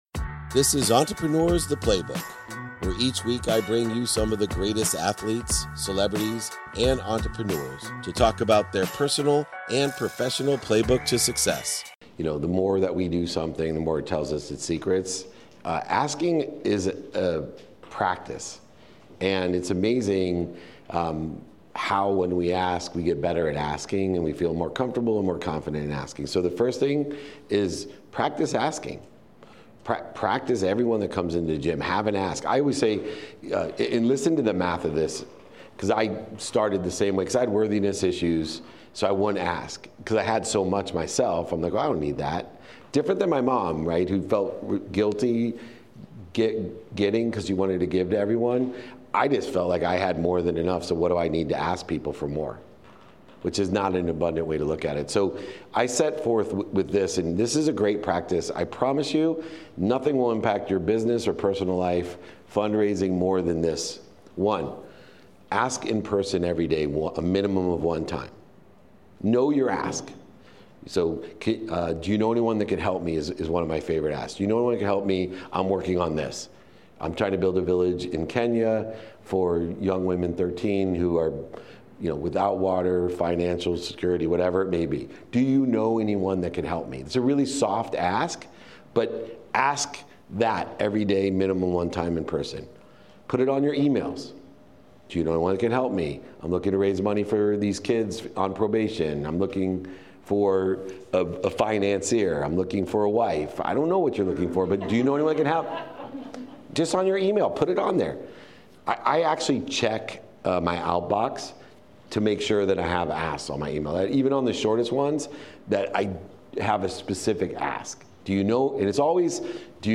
Today’s episode is from a TAG talk on Transparency, Acceptance, and Growth where I explore a simple yet powerful practice that has significantly influenced my path to success: the art of asking.